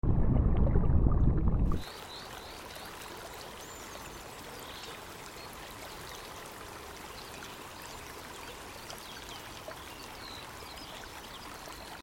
Mp3 Sound Effect Rising up from crystal-clear waters, the view opens to a vibrant alpine meadow, crowned by the snow-capped peak of a majestic mountain in the heart of the Pacific Northwest. The shimmering creek reflects warm sunlight, while the breeze moves gently through the tall grasses, accompanied by the peaceful sound of birdsong—pure mountain serenity 😌 Rising Up From Crystal Clear Waters, Sound Effects Free Download.